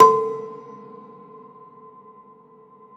53o-pno14-C3.wav